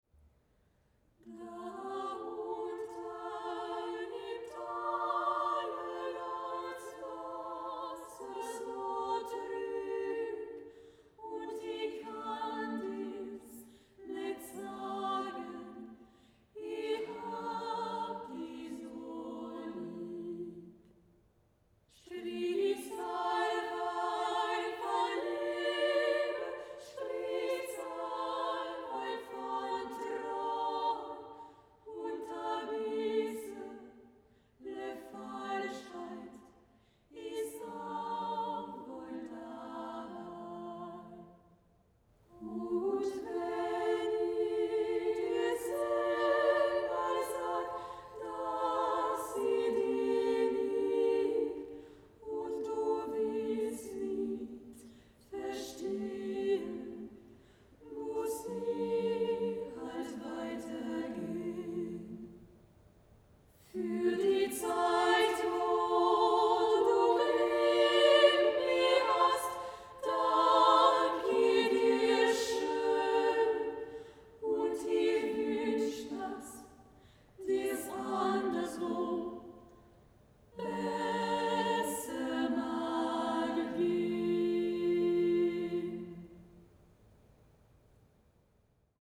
Epoque :  Romantique
Genre :  Mélodie / lied
Style :  A cappella
Enregistrement tutti